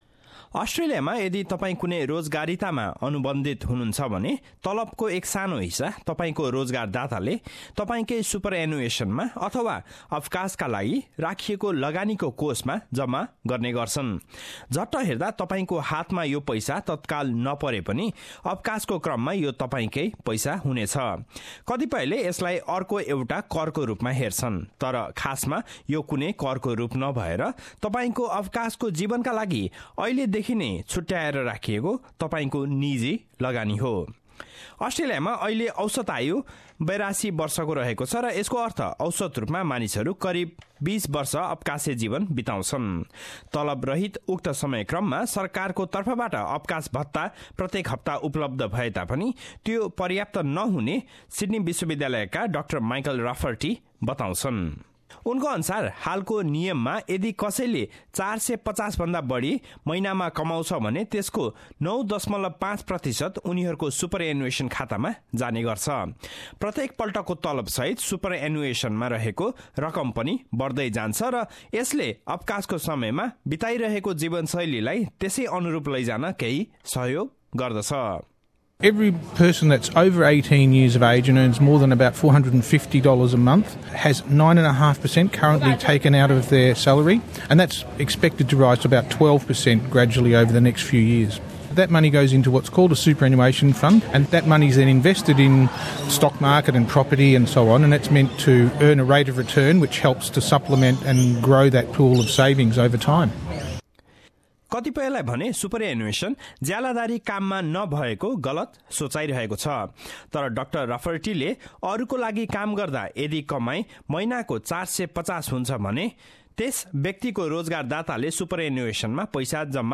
रिपोर्ट।